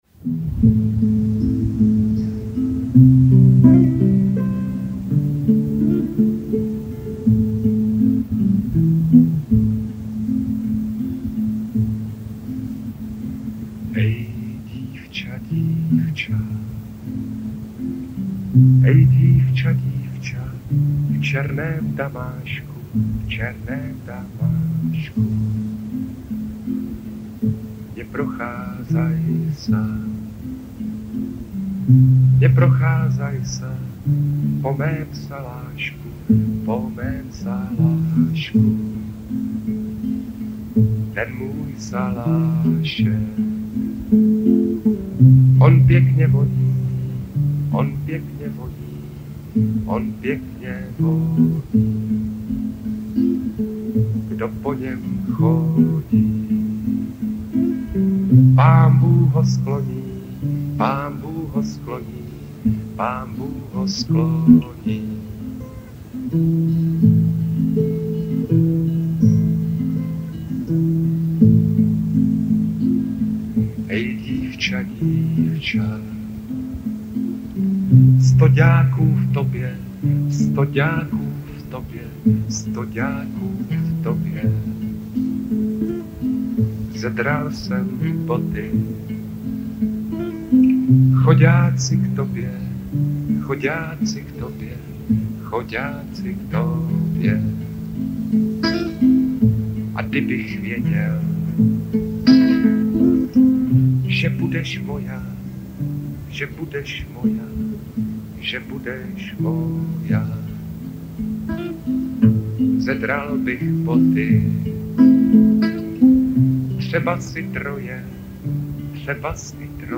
Lidové písně zpívané | Jarek Nohavica